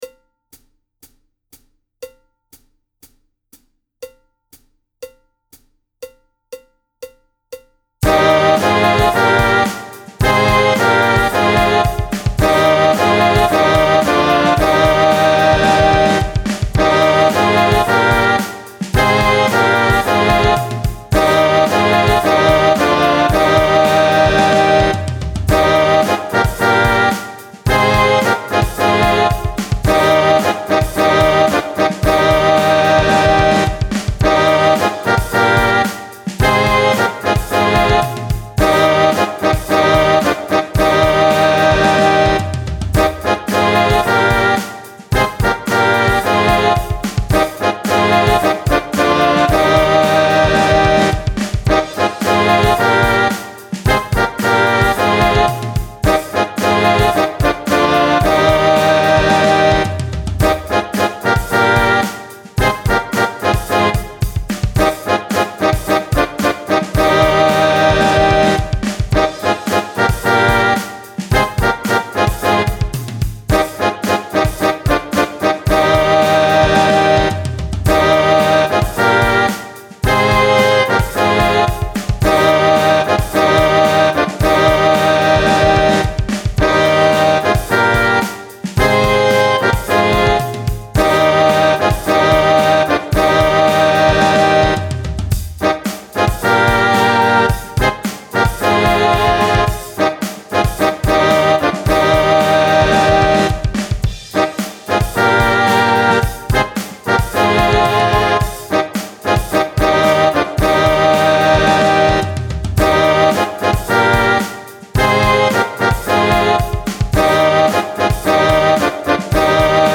– die Stimmung ist 440Hertz
– der Einzähler des Playbacks beginnt 4 Takte vor dem Song
– das Playback ist aktuell noch eine Midi-Version
Zweistimmige Übung - Tempo 110
Nr. 1 bis 7 | Tutti